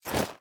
Minecraft Version Minecraft Version 25w18a Latest Release | Latest Snapshot 25w18a / assets / minecraft / sounds / item / armor / equip_gold6.ogg Compare With Compare With Latest Release | Latest Snapshot
equip_gold6.ogg